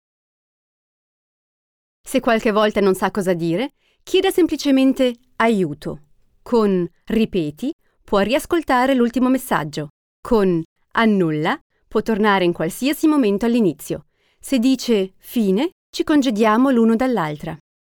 Schauspielerin und Sprecherin, Muttersprache Italienisch, Deutsch mit F od. I Akzent, E und F mit I Akzent, breites Spektrum an Stimmlagen , Gesang.
Sprechprobe: eLearning (Muttersprache):
Native speaker (italian) and actress.Can speak german, french and english with french or italian accent.